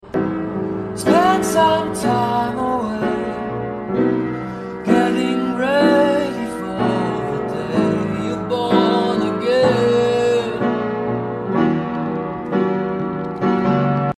Mercedes Benz W124 . . sound effects free download